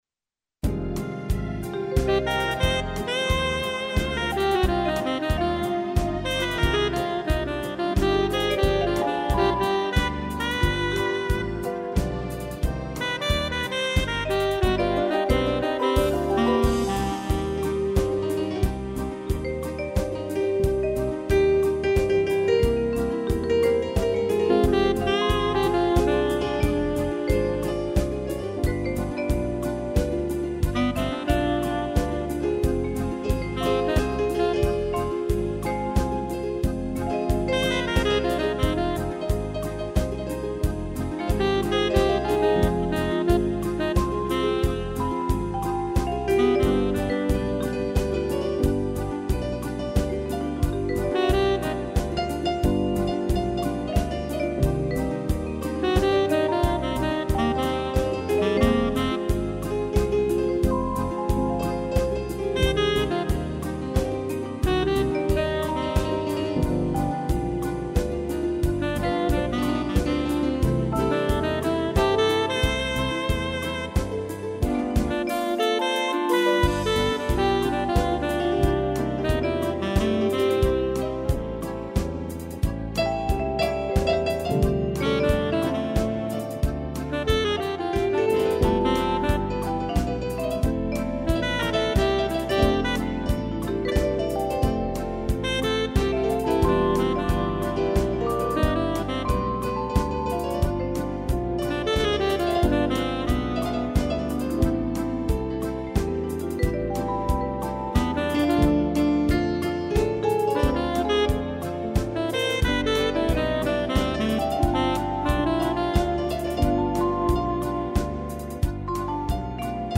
vibrafone e sax
(instrumental)